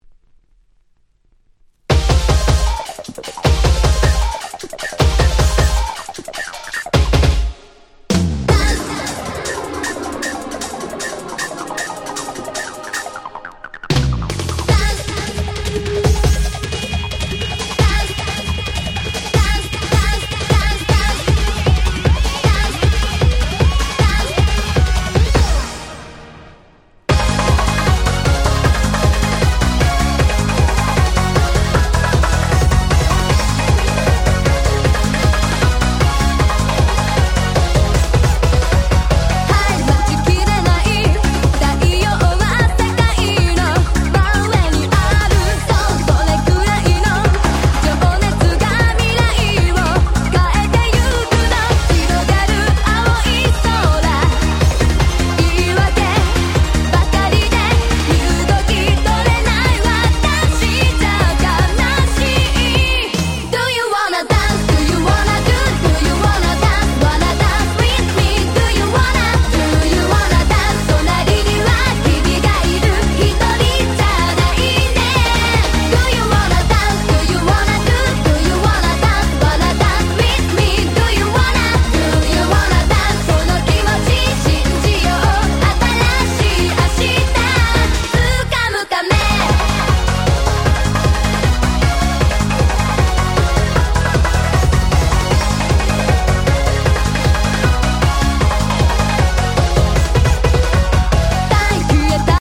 01' Very Nice J-Pop / Super Euro Beat !!